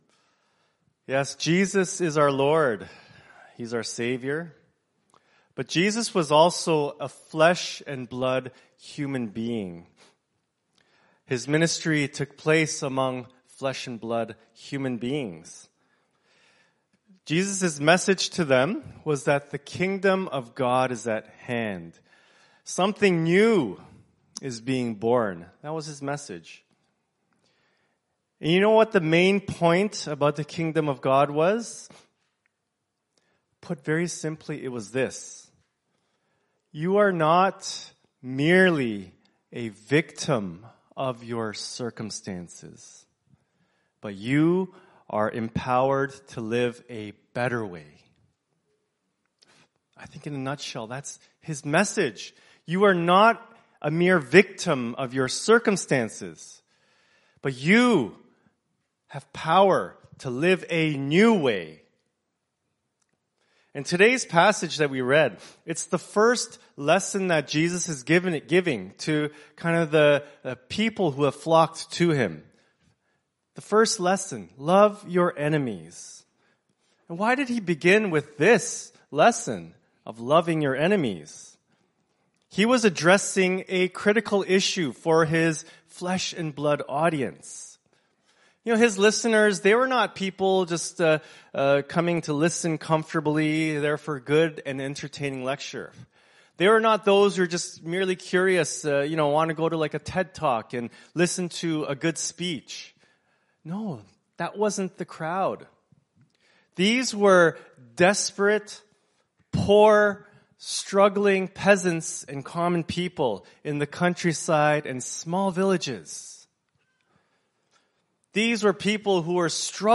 Scripture Passage Luke 6:27-38 Worship Video Worship Audio Sermon Script Jesus is our Lord.